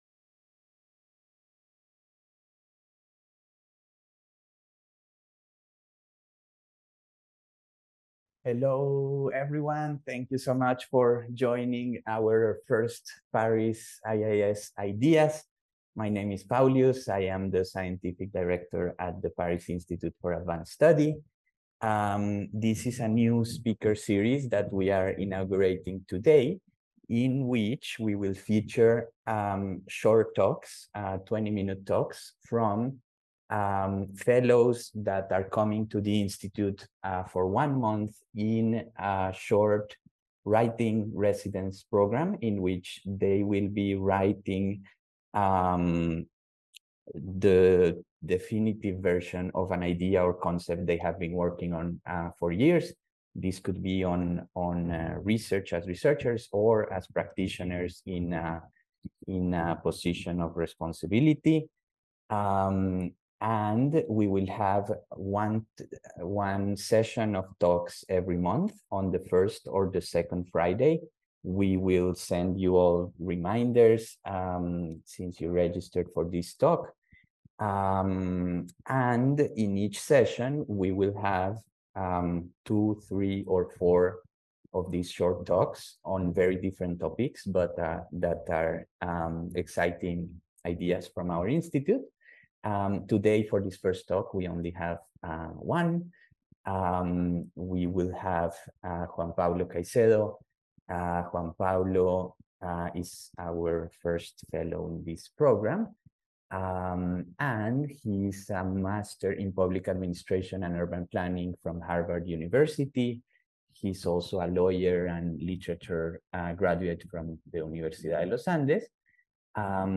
Le cycle "Paris IAS Ideas" propose des présentations courtes de chercheurs de l'Institut, suivies d'échanges avec le public.